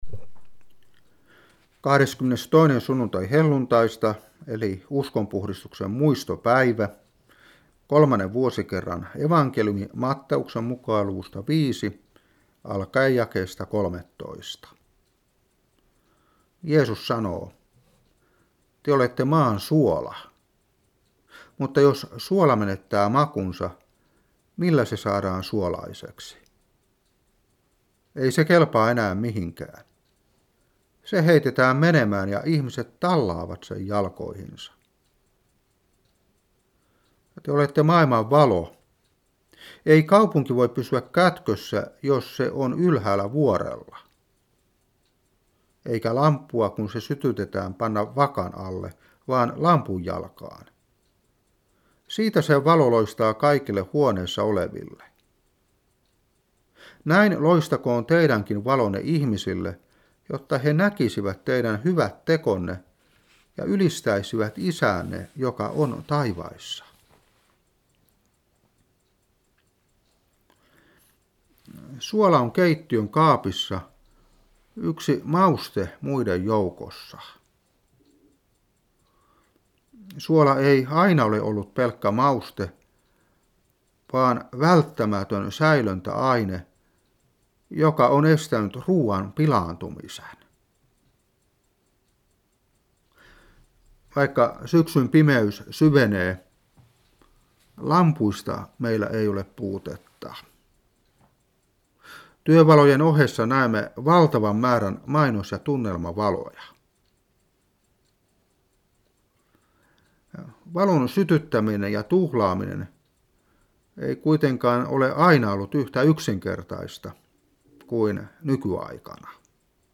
Saarna 2012-10.